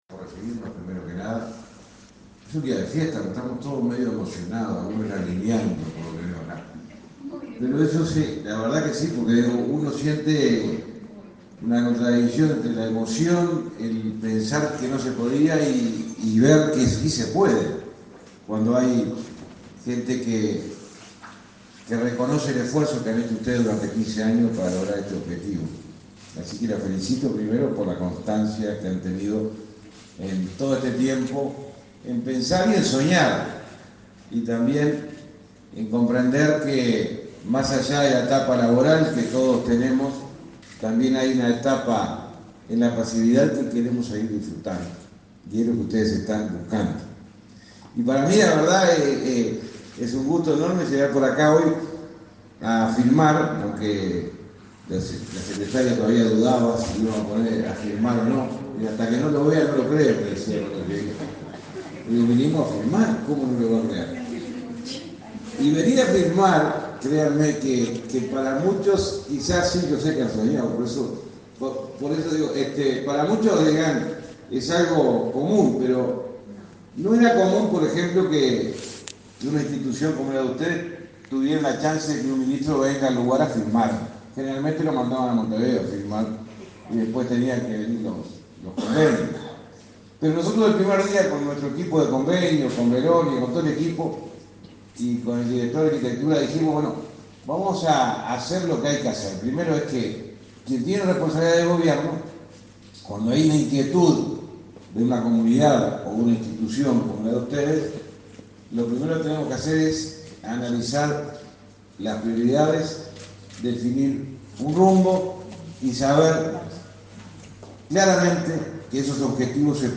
Palabras del ministro de Transporte y Obras Públicas, José Luis Falero
Palabras del ministro de Transporte y Obras Públicas, José Luis Falero 27/06/2023 Compartir Facebook X Copiar enlace WhatsApp LinkedIn El ministro de Transporte y Obras Públicas, José Luis Falero, firmó, este 27 de junio, un convenio en Santa Clara de Olimar con la Asociación de Jubilados y Pensionistas.
Falero acto.mp3